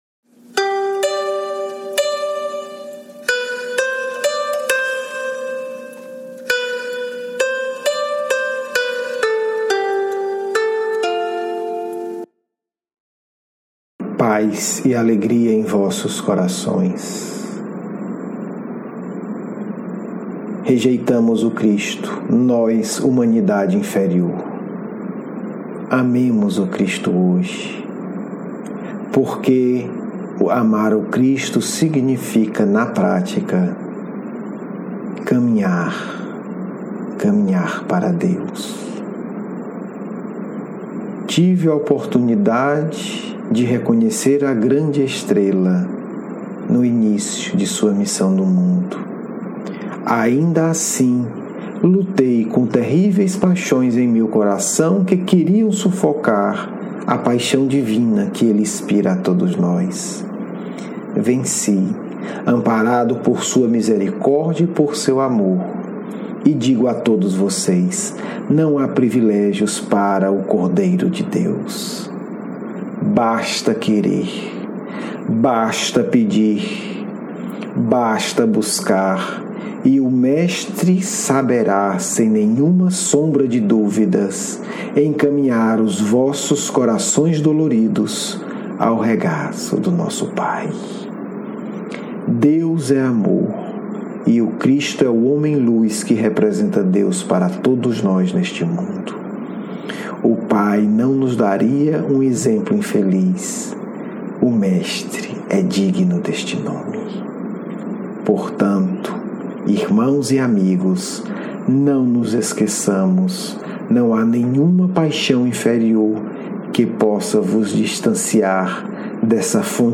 Diálogo mediúnico